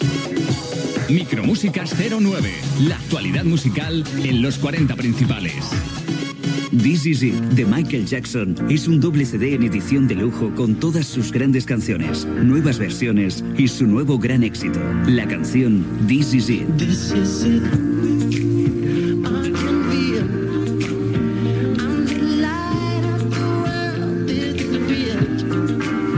Indictiu del programa i presentació d'un tema musical de Michael Jackson.